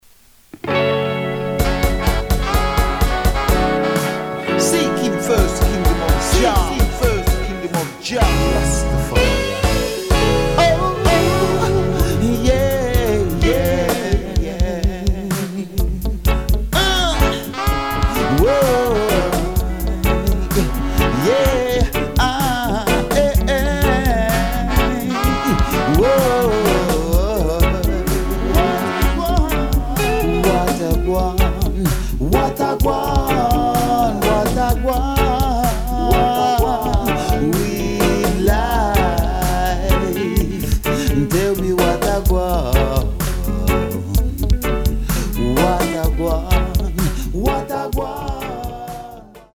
Recorded: Shanti Studio's